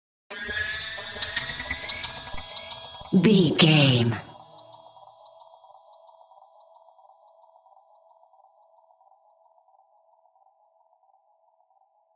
High Witch Stinger.
In-crescendo
Atonal
scary
ominous
suspense
eerie
spooky
Horror Synths
Scary Piano
Scary Strings